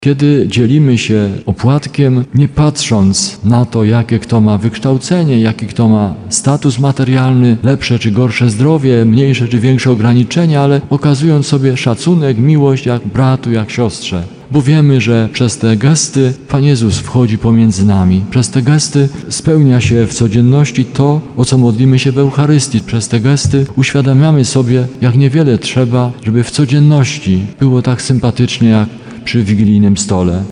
Była też Msza Święta w Sanktuarium św. Rity. Przewodniczył jej biskup Stanisław Salaterski, który podkreślał wartość takich spotkań.